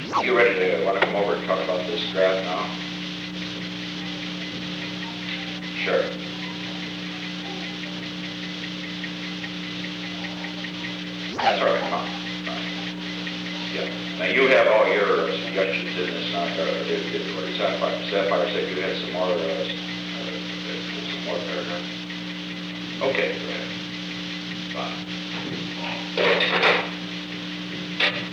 On January 24, 1972, President Richard M. Nixon and Henry A. Kissinger met in the President's office in the Old Executive Office Building at an unknown time between 3:09 pm and 3:15 pm. The Old Executive Office Building taping system captured this recording, which is known as Conversation 317-009 of the White House Tapes.